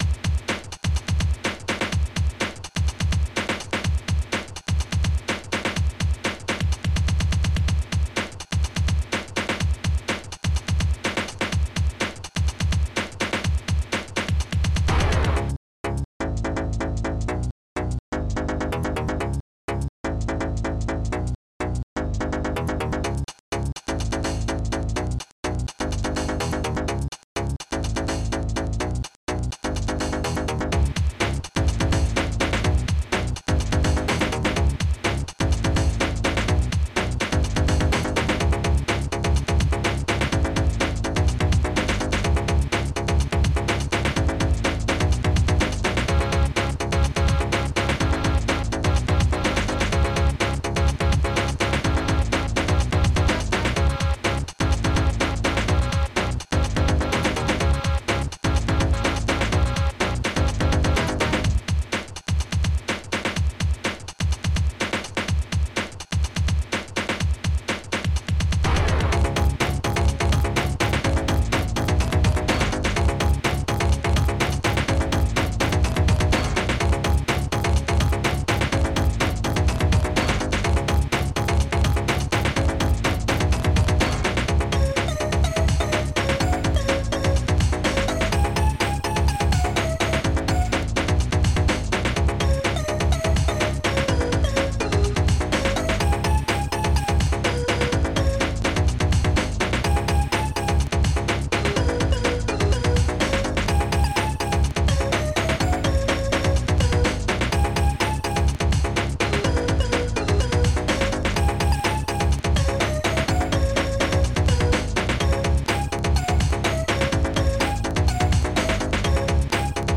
Digital Symphony Module
Instruments dum1 dum2 dum3 dum4 dum5 dum7 dum9 pinvoice hihat3 hihat4